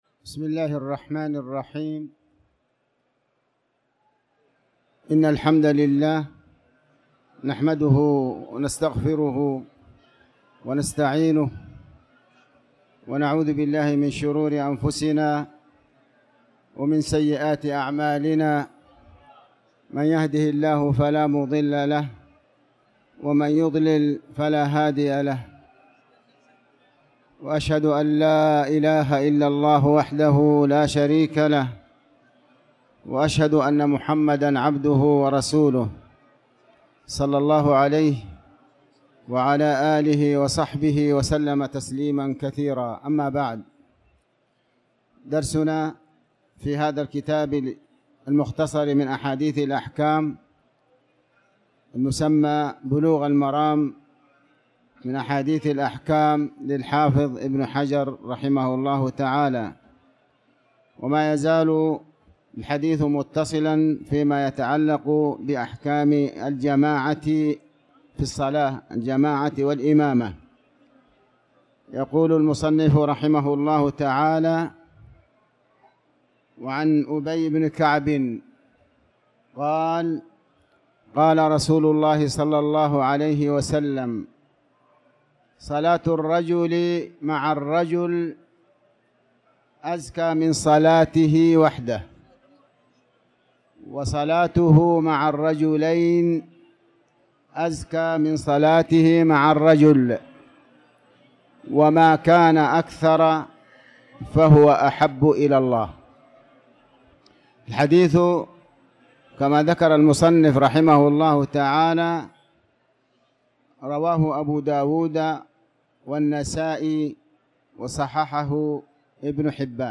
تاريخ النشر ٢٧ شعبان ١٤٤٠ هـ المكان: المسجد الحرام الشيخ